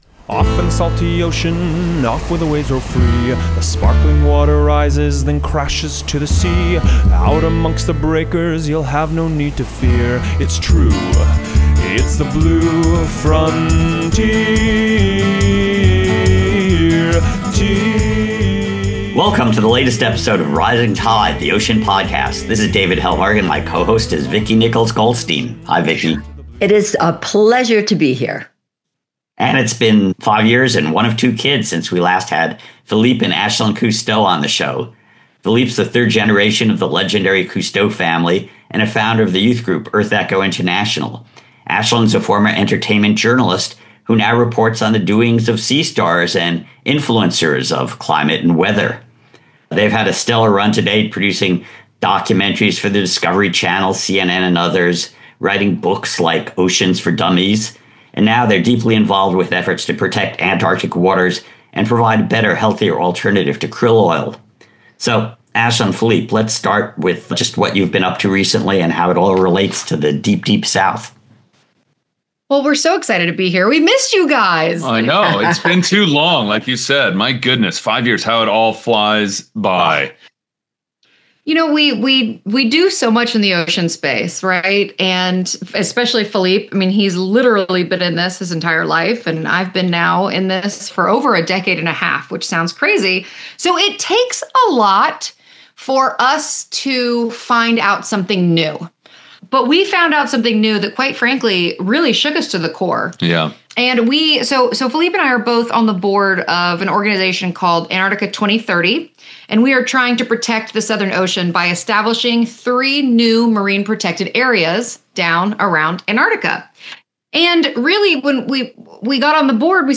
So, a fascinating encounter with a dynamic ocean duo.